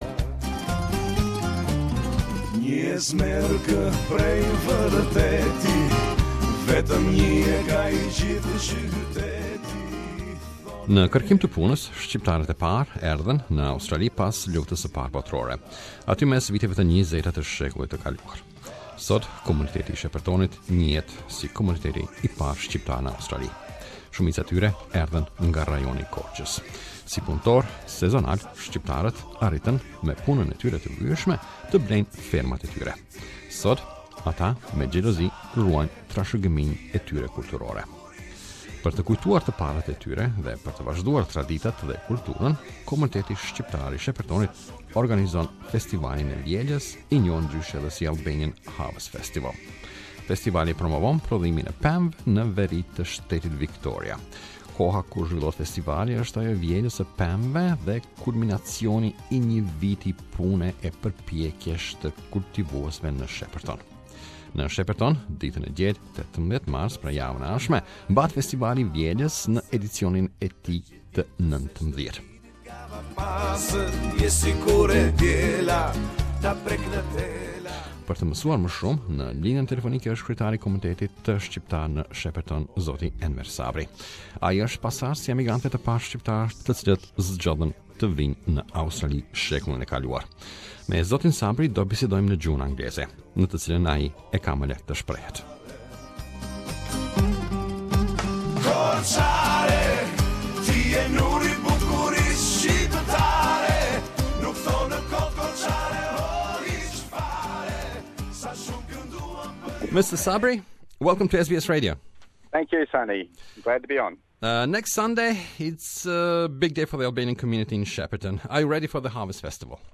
Albanian Harvest Festival in Shepparton, interview